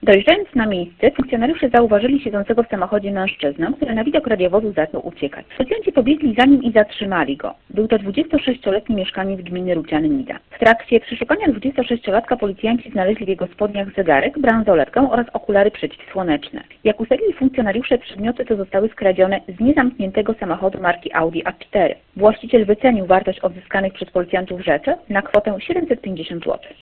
-Na miejsce natychmiast skierowano patrol, mówi